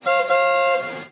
Sonido de claxon 1
bocina
claxon
Sonidos: Transportes
Sonidos: Ciudad